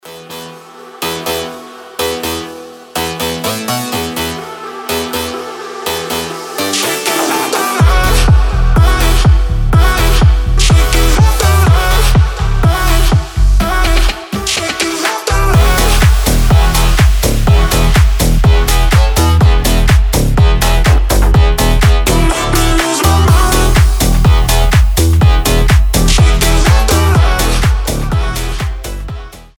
• Качество: 320, Stereo
EDM
мощные басы
Bass House
electro house